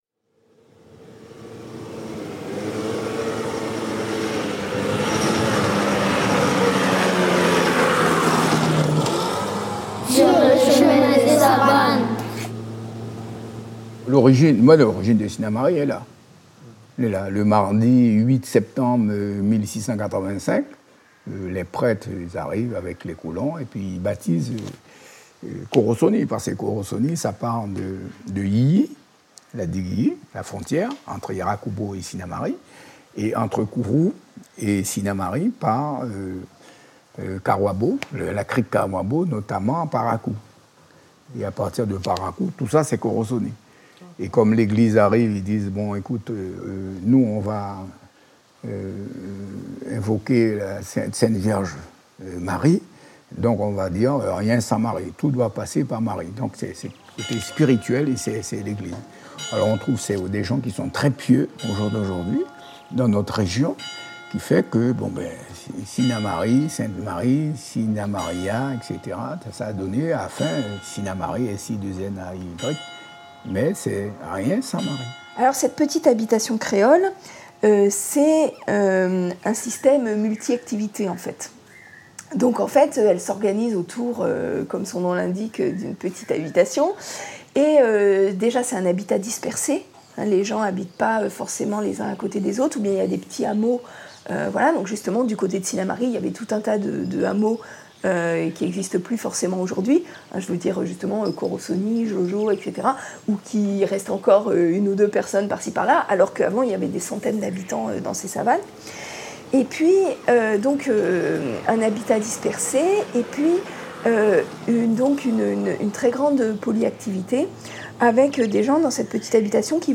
Écoutez les témoignages d’agriculteurs, habitants, scientifiques… et laissez-vous surprendre par des ambiances sonores, des extraits musicaux et des contes lors de vos trajets en voiture pour relier les points d’intérêt.